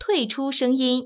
conf-exit_sound.wav